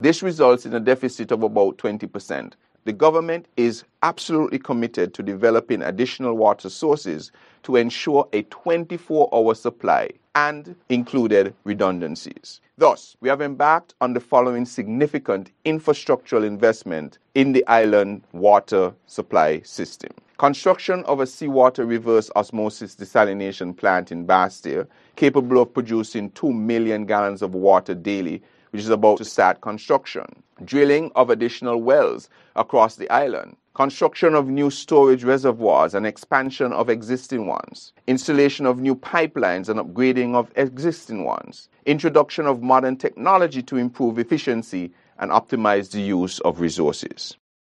As such, Minister of Water Services, the Hon. Konris Maynard, in an address on Water Day, explained that the demand for water in St. Kitts is 6 million gallons per day, while the supply capacity is about 5 million gallons per day: